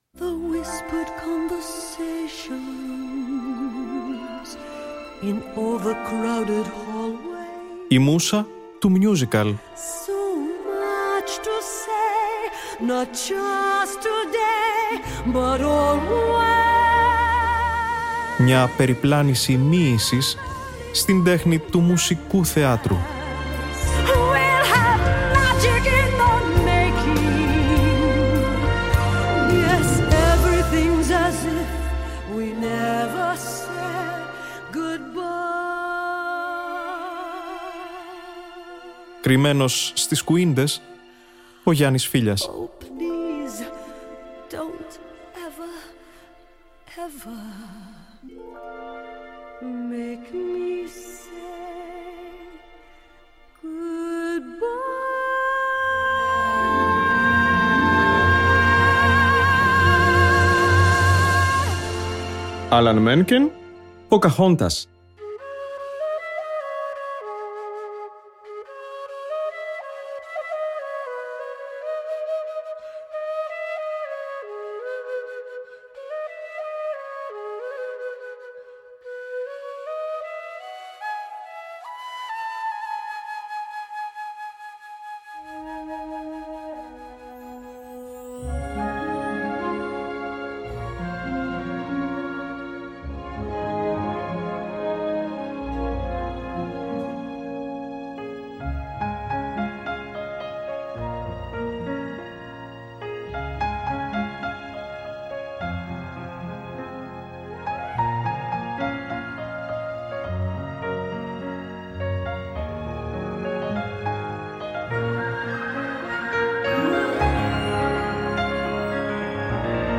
Ένα υπέροχο παραμύθι μας περιμένει αυτή στην εκπομπή που μεταδόθηκε την Τετάρτη 5 Ιουνίου στη «Μούσα του Musical».